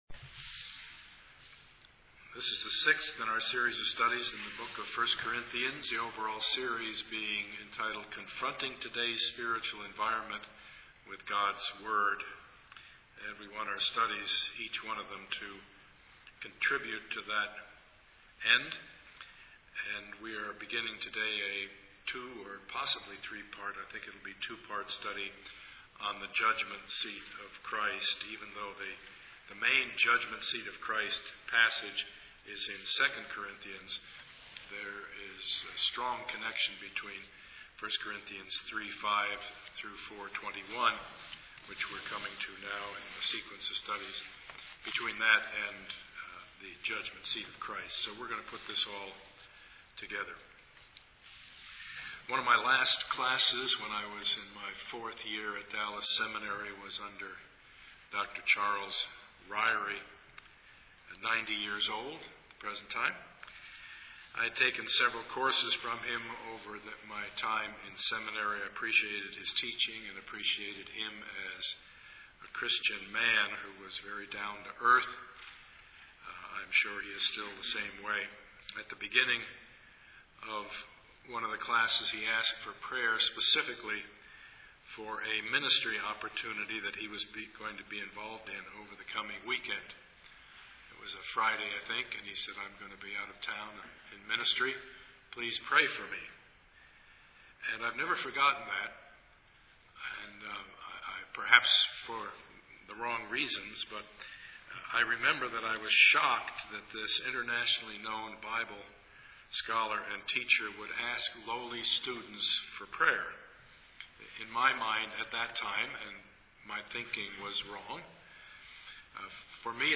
Service Type: Sunday morning
Part 6 of the Sermon Series